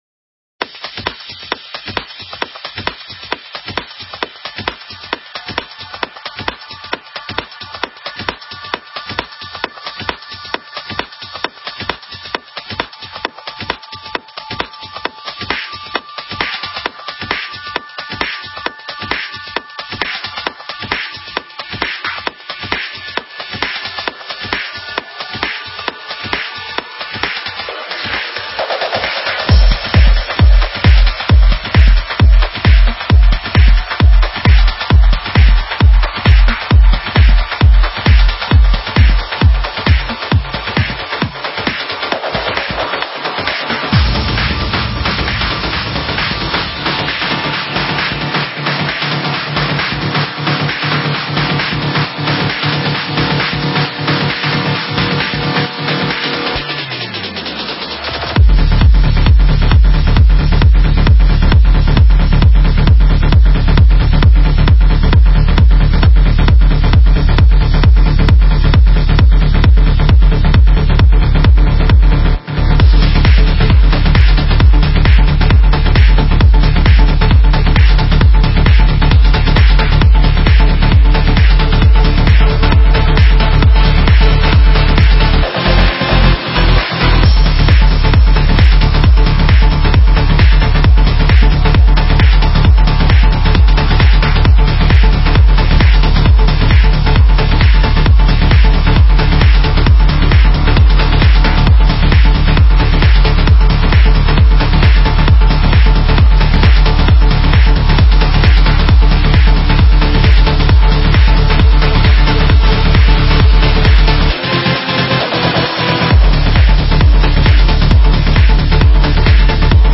Стиль: Tech Trance